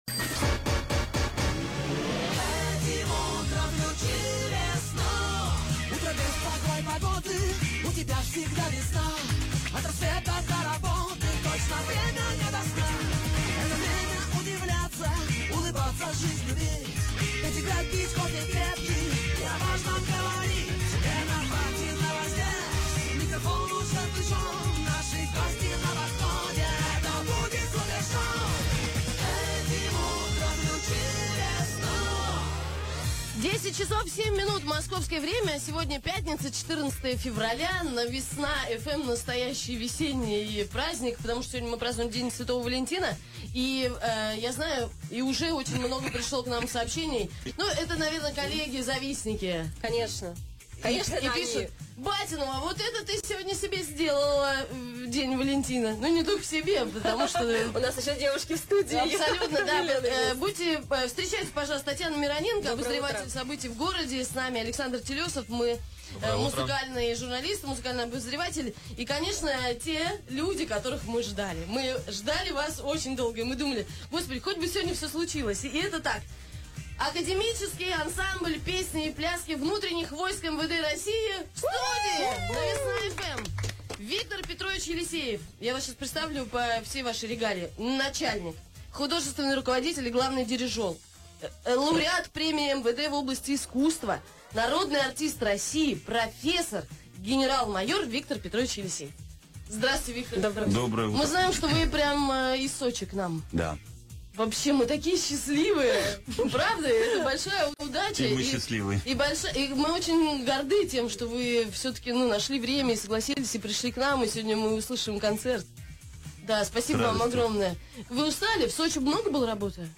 Количеством одновременно находящихся человек в студии радио!
Но на эфир пришло 50 человек.
Гости: Академический ансамбль песни и пляски Внутренних войск МВД России